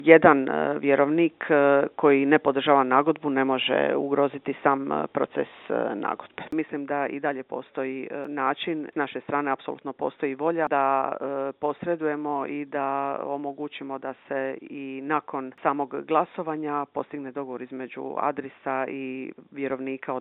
Zamjenica izvanrednog povjerenika u Agrokoru Irena Weber otkrila nam je i kada predaju nagodbu Trgovačkom sudu i kakvu odluku očekuje: